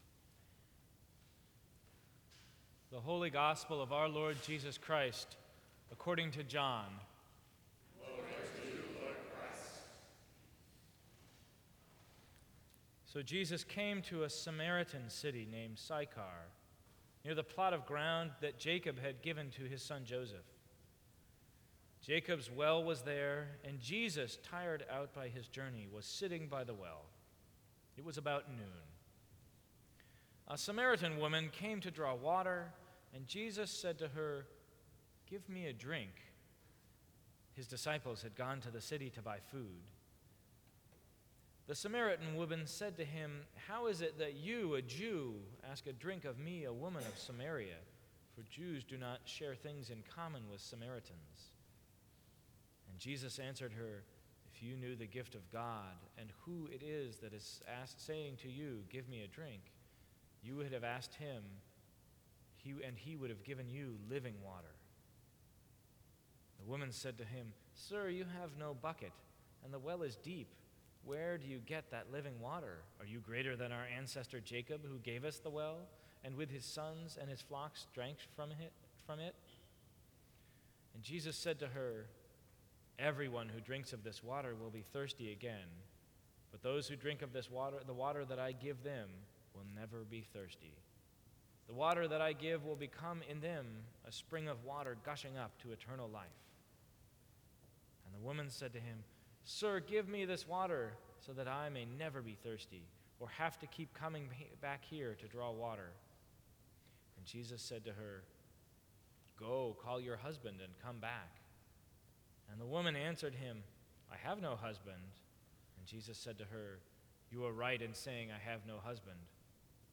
Sermons from St. Cross Episcopal Church March 23, 2014.